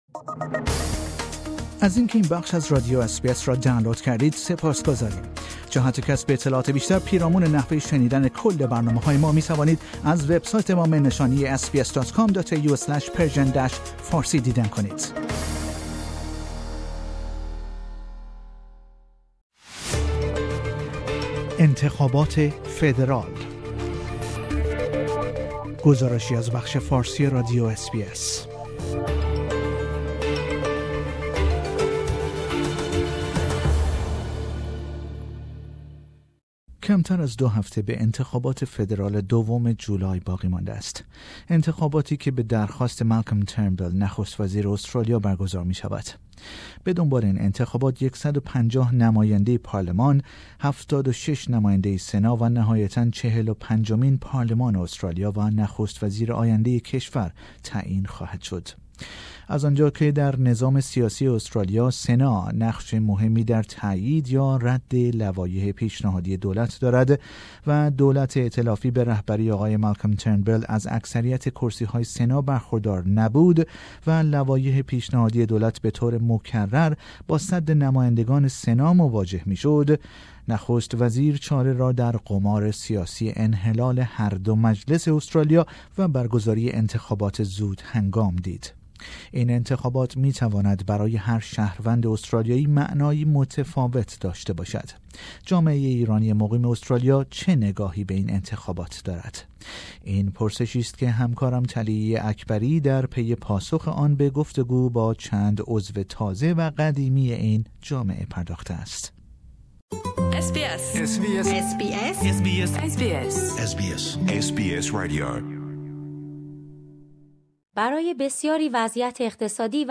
This is a report about Persian speaking community's hopes and expectation from the upcoming federal election in Australia.